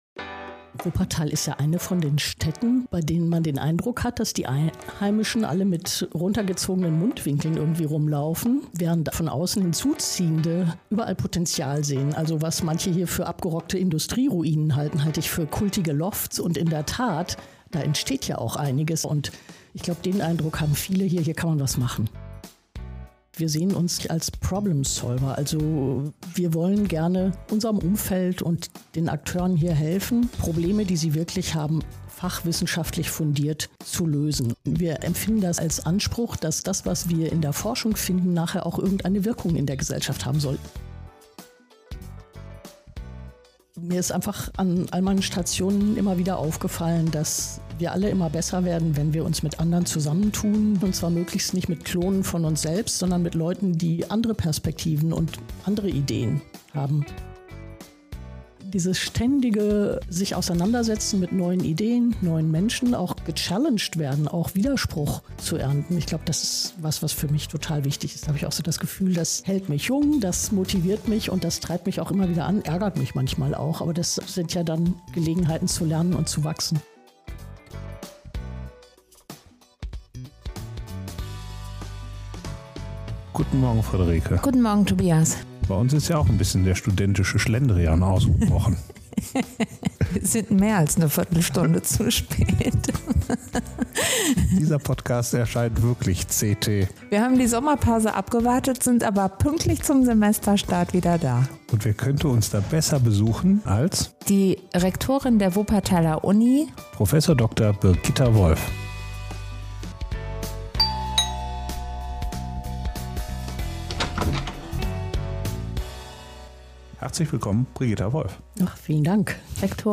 Wie das funktioniert und was sie persönlich antreibt, darüber spricht sie mit uns in dieser Folge von »Sag mal, Wuppertal!«.
In "Sag mal, Wuppertal!" holen wir spannende Menschen aus der Stadt vors Mikrofon und hören zu, was sie über das Leben entlang der Wupper und auf den umliegenden Hügeln zu erzählen haben.